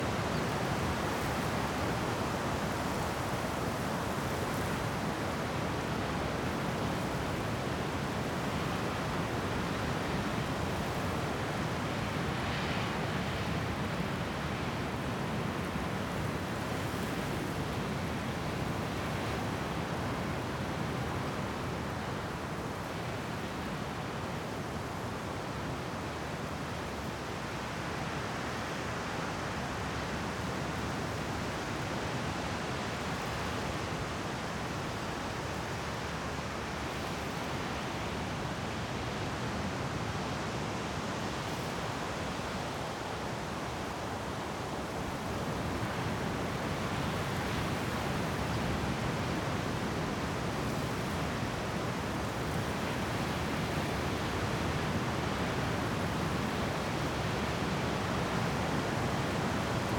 Sea.wav